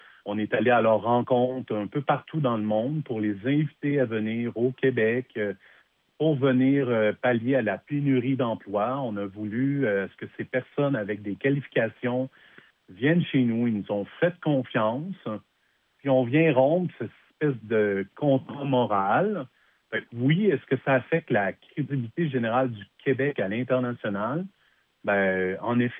en entrevue avec le Service de nouvelles de M105.